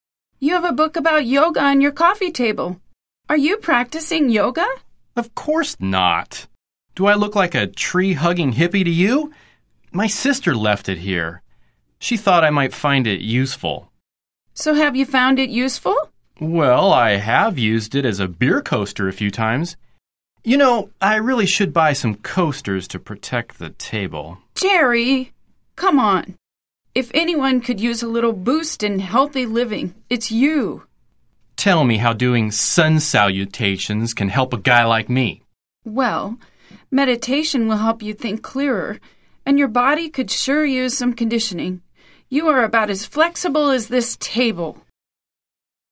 聽力大考驗：來聽老美怎麼說？